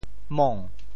某 部首拼音 部首 木 总笔划 9 部外笔划 5 普通话 mǒu 潮州发音 潮州 mong2 文 中文解释 某 <代> (象形。
mong2.mp3